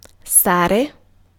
IPA : [stɪk]